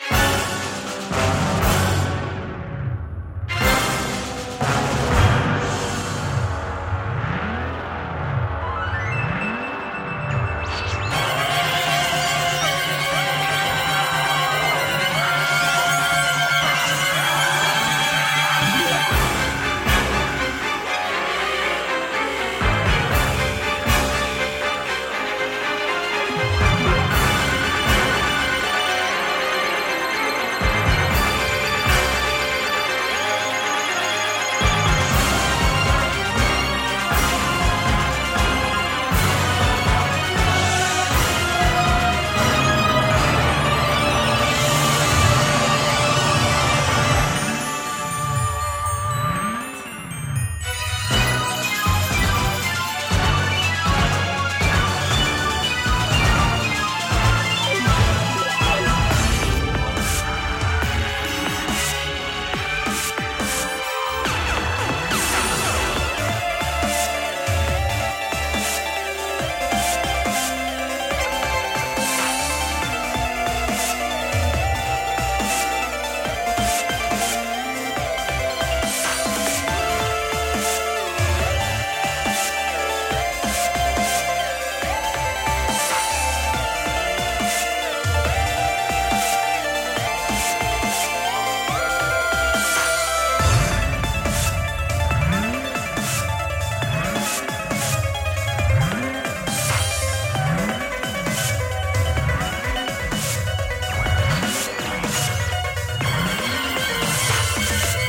battle music